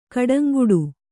♪ kaḍaŋguḍu